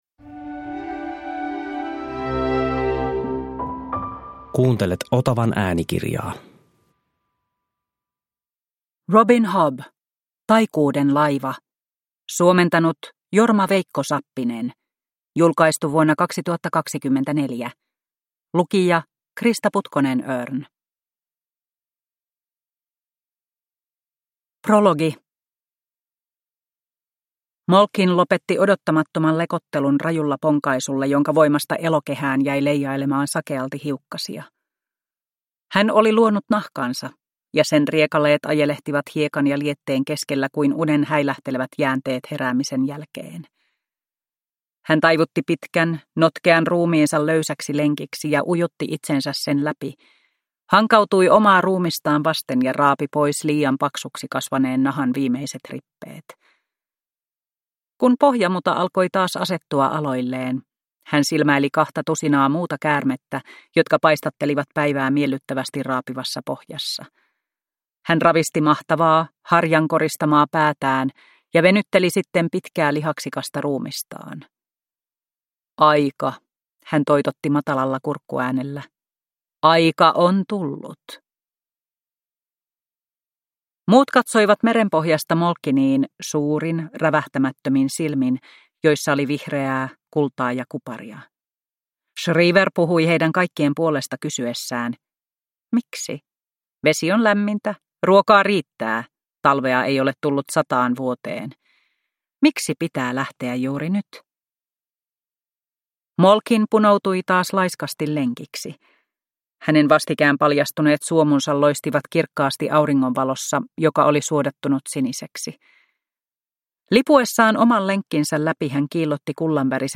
Taikuuden laiva – Ljudbok